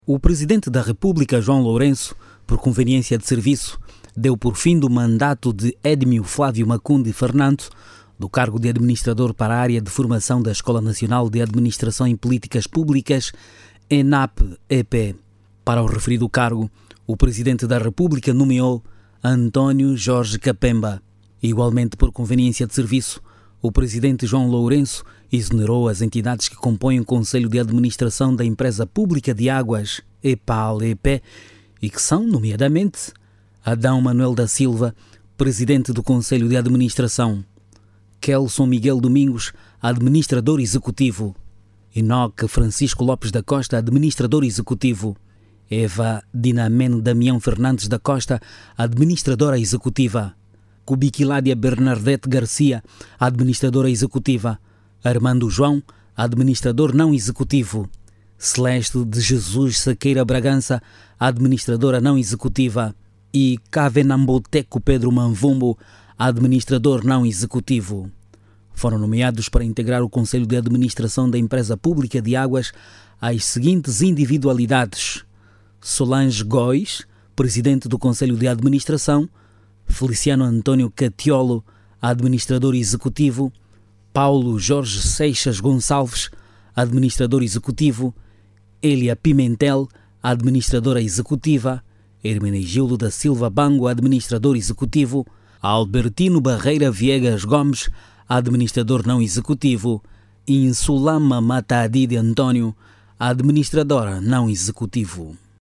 O Presidente da República, João Lourenço, procedeu esta sexta-feira, 28 de novembro, a alterações e movimentações nos Conselhos de Administração da EPAL e da Escola Nacional de Administração e Políticas Públicas (ENAPP-EP). Jornalista